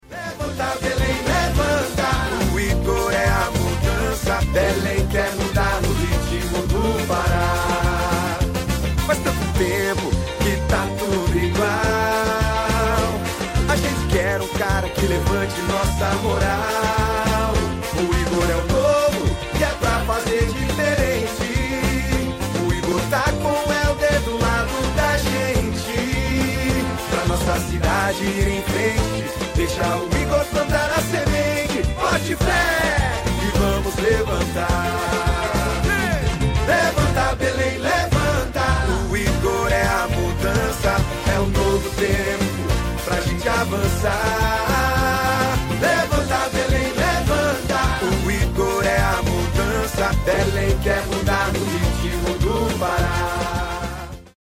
Levanta, Belém Jingle do sound effects free download